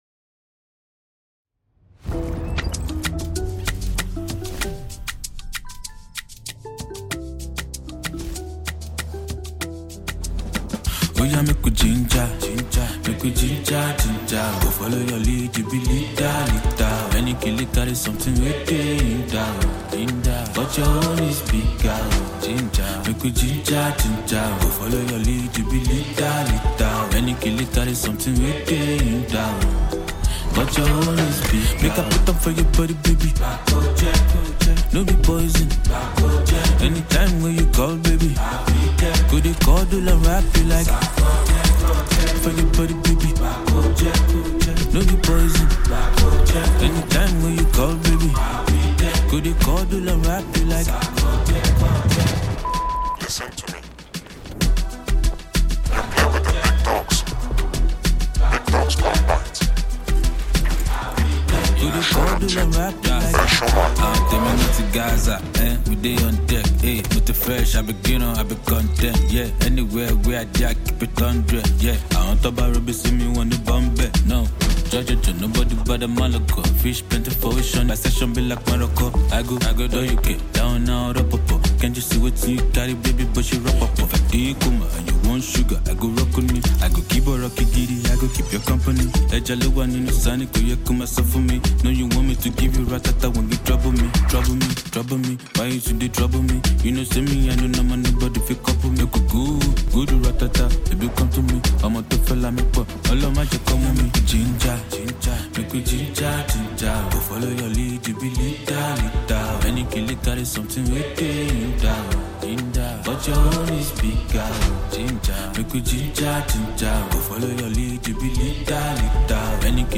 a hip hop song